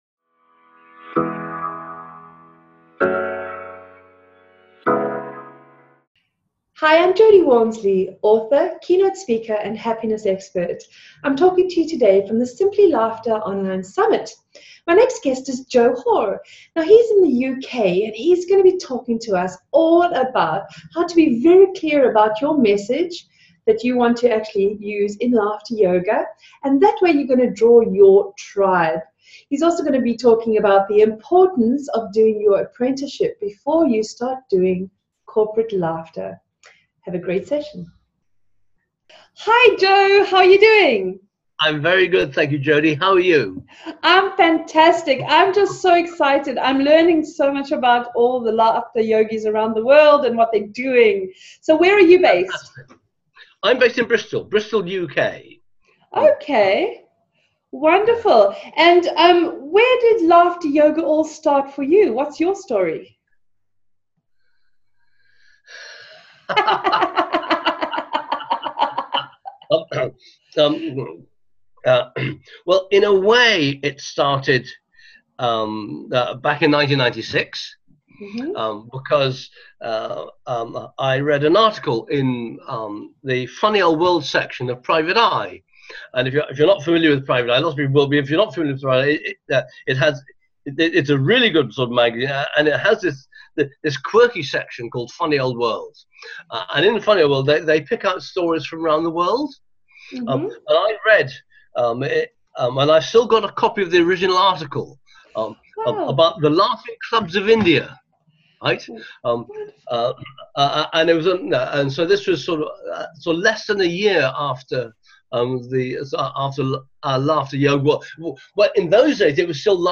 Main points in the interview: The most important aspect of life is personal wellbeing.